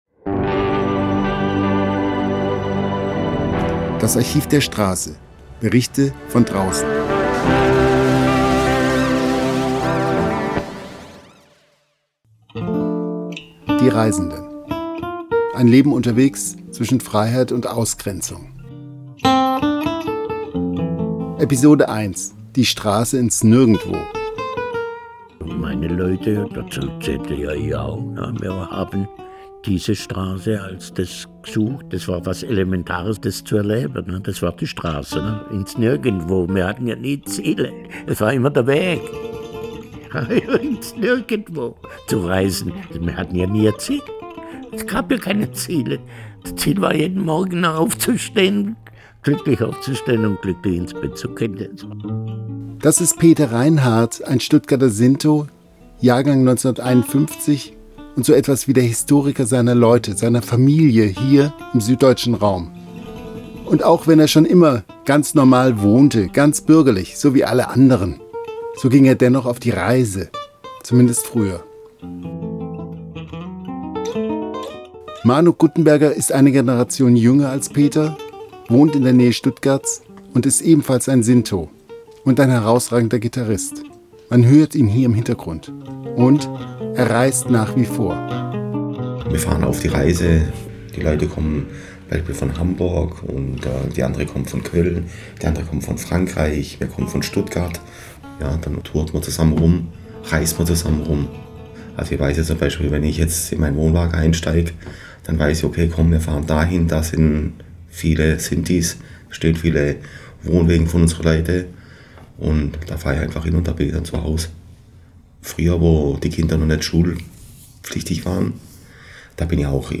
Musik